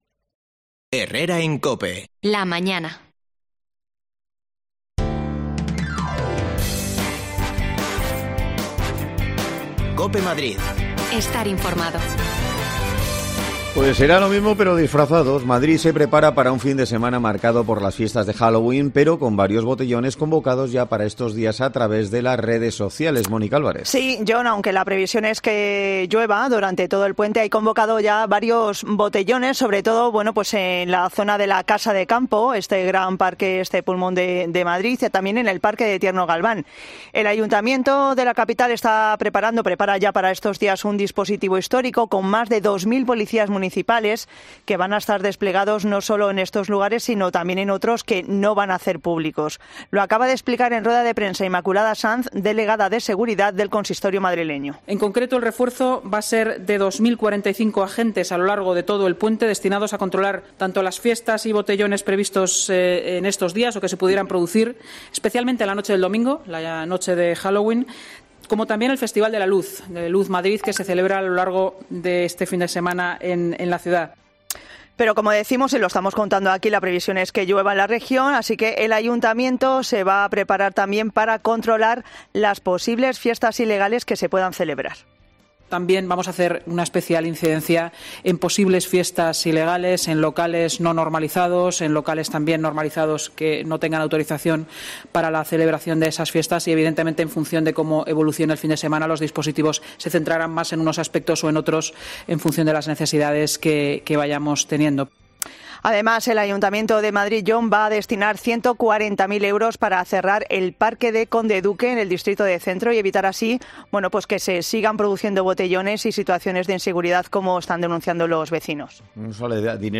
Te contamos las últimas noticias de la Comunidad de Madrid con los mejores reportajes que más te interesan y las mejores entrevistas , siempre pensando en el ciudadano madrileño.